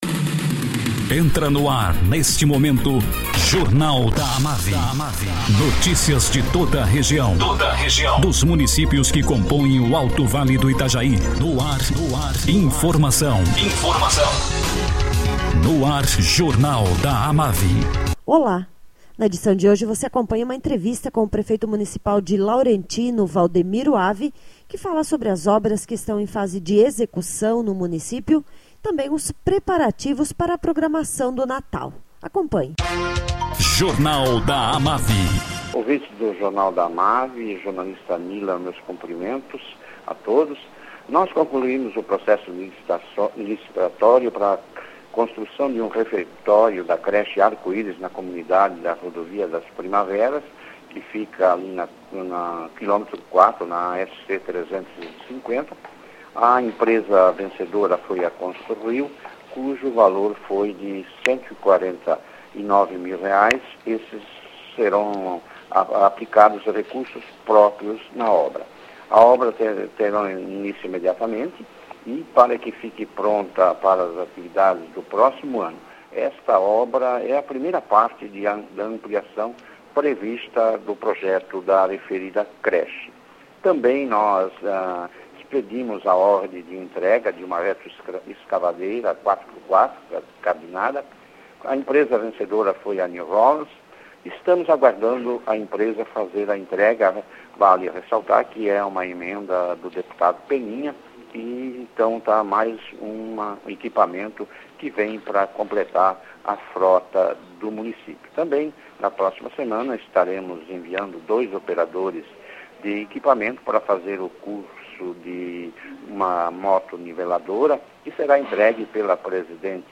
Prefeito municipal de Laurentino, Valdemiro Avi, fala sobre obras que estão sendo executadas no município e preparativos para o Natal.